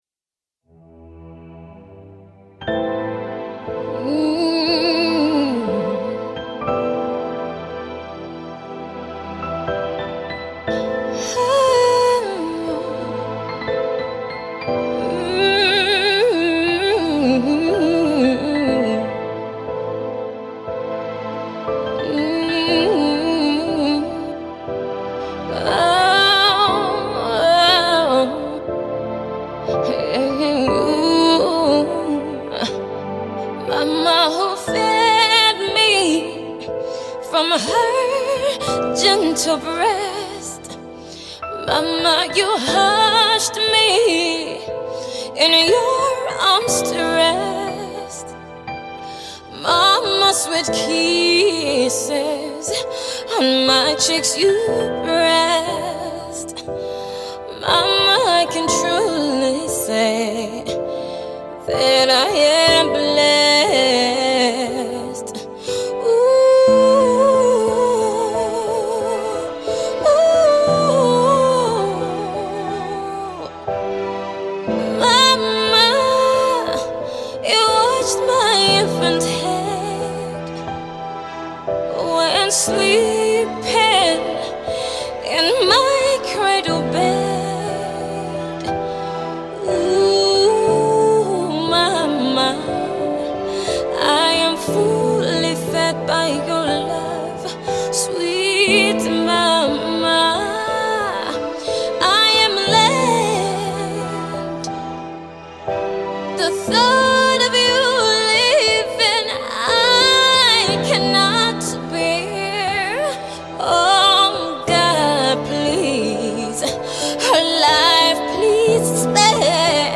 an acoustic heartfelt ballad